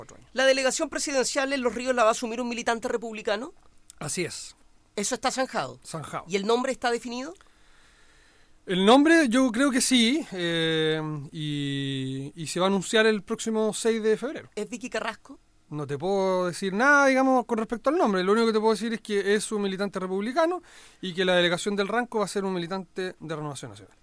En entrevista con Radio Bío Bío, el diputado electo Leandro Kunstmann (republicano) aseguró que “está zanjado” que la Delegación Presidencial de Los Ríos la asumirá un militante republicano, cuyo nombre ya estaría definido, pero evitó darlo a conocer.